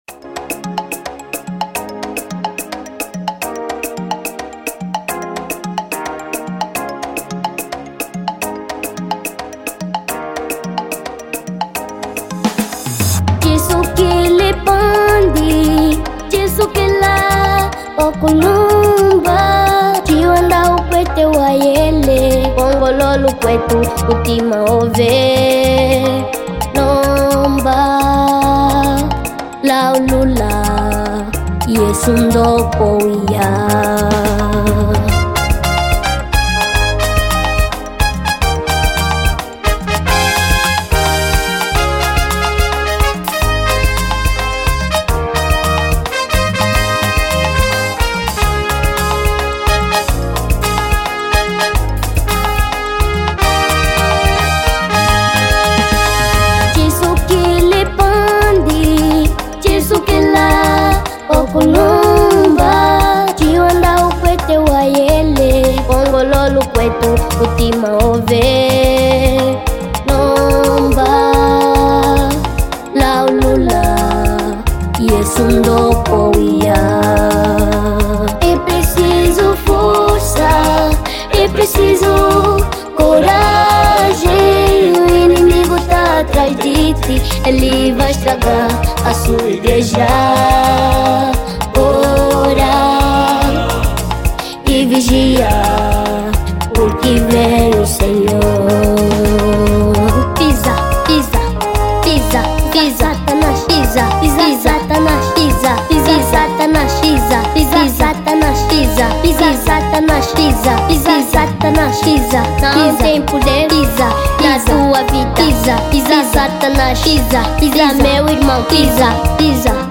| Gospel